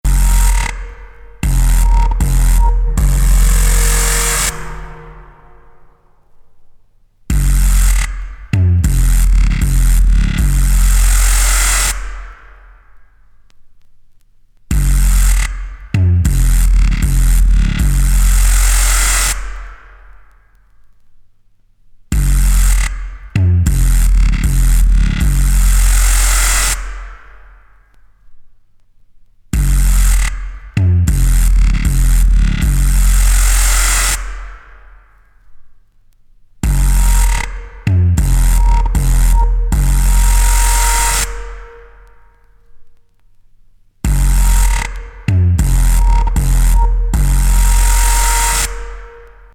普遍的でメランコリックな世界観で、未だ廃れることなく作品群を放っている